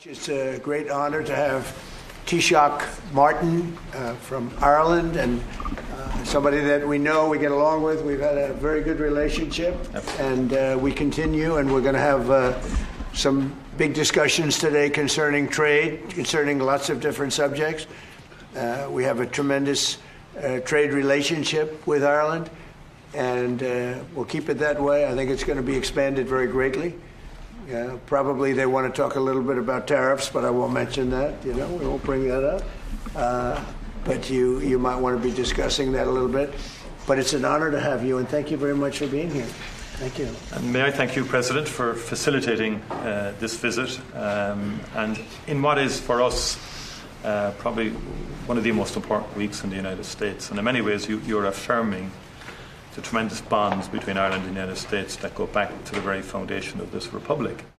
They’ve been speaking to the media in the last few minutes: